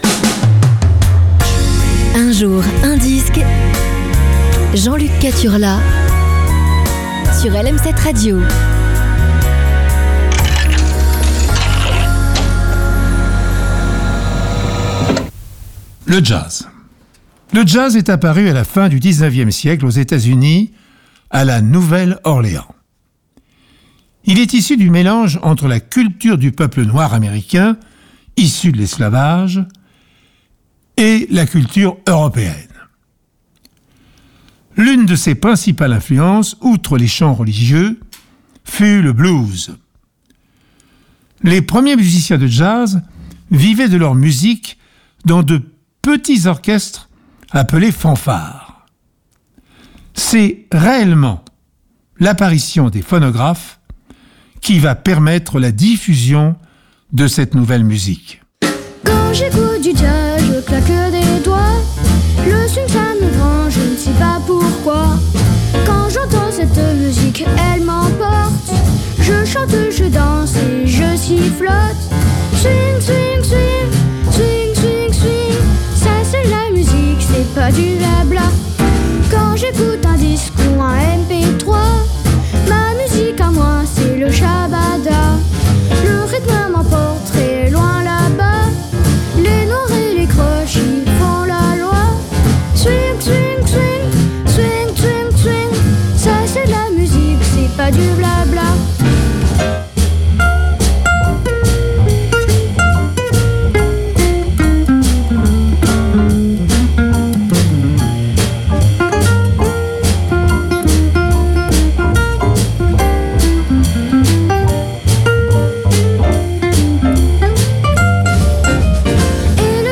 racontée par Pierre Bellemare